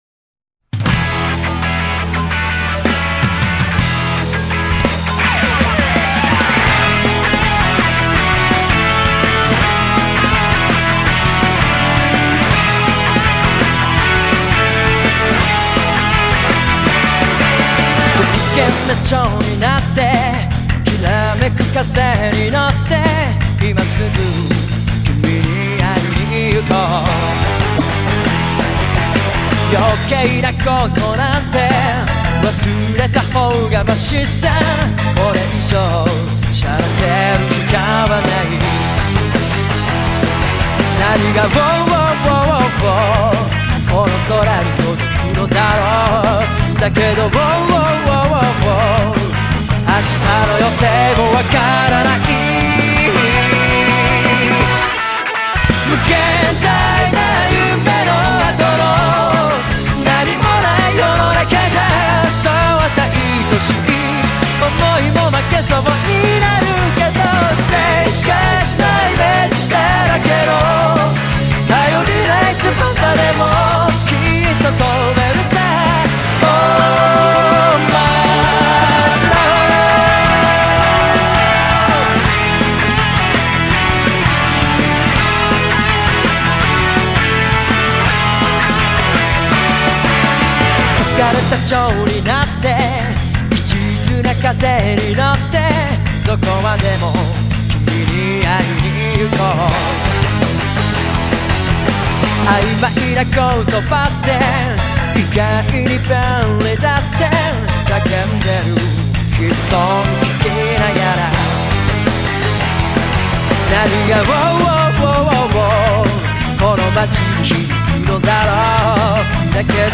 sigla giapponese